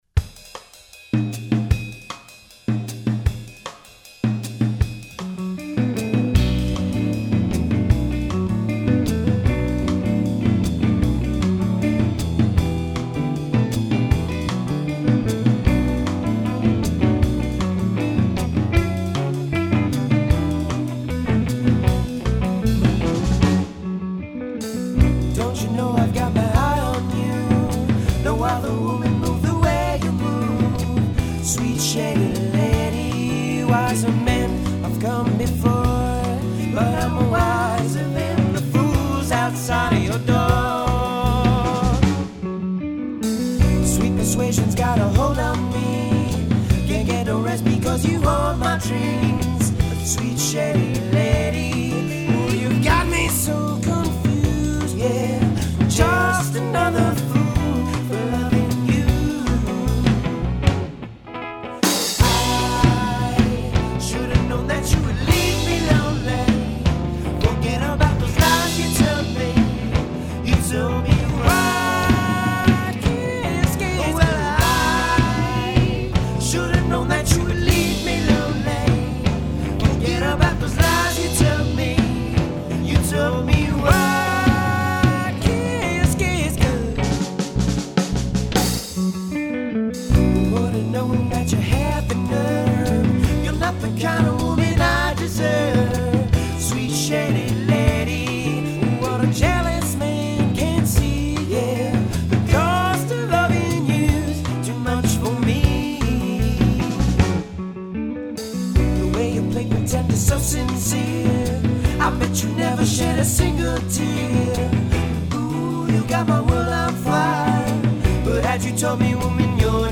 Teen band battle